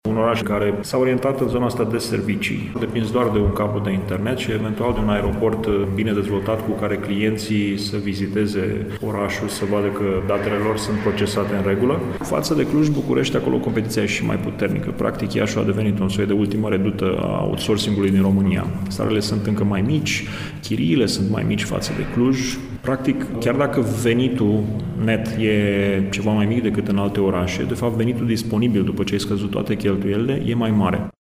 Într-un interviu acordat colegului nostru